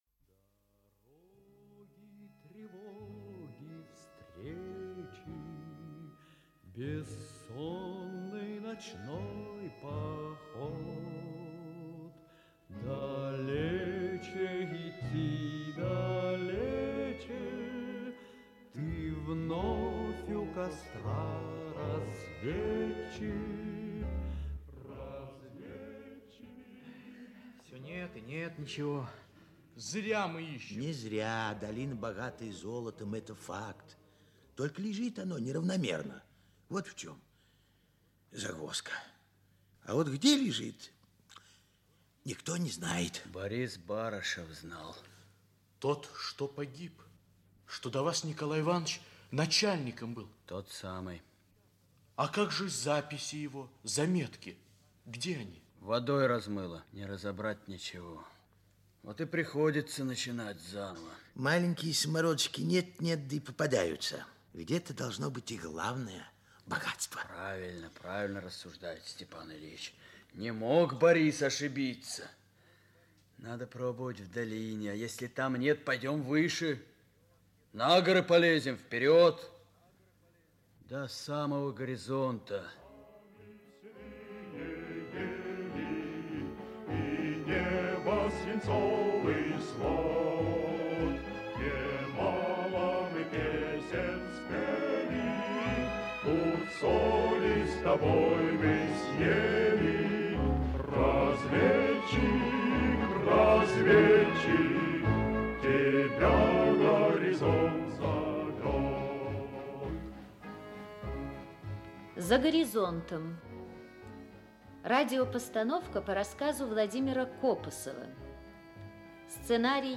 Аудиокнига За горизонтом | Библиотека аудиокниг
Aудиокнига За горизонтом Автор Владимир Копосов Читает аудиокнигу Актерский коллектив.